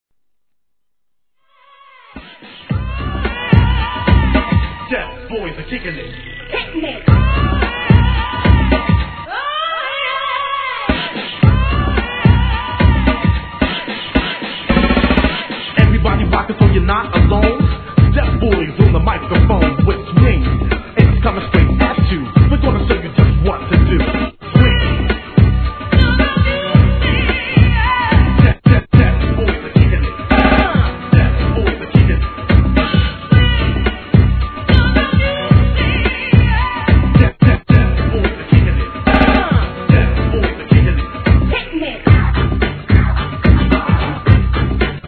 HIP HOP/R&B
(121 BPM)
(110 BPM)